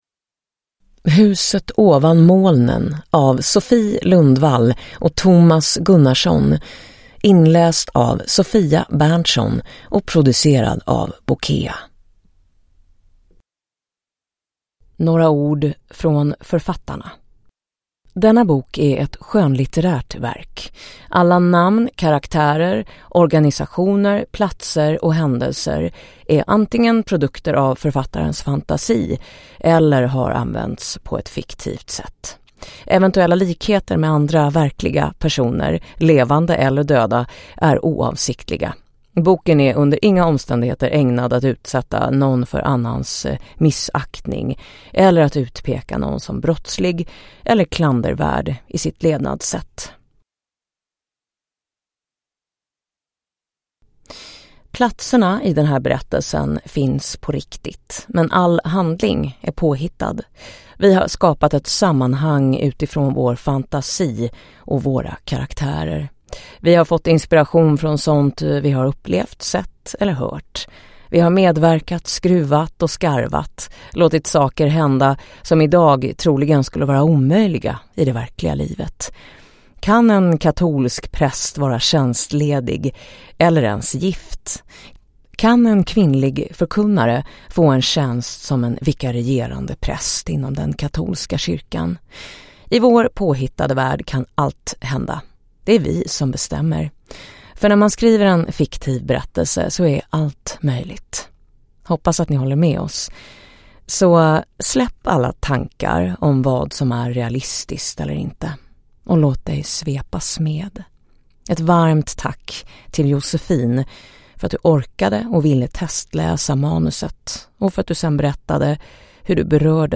Huset ovan molnen – Ljudbok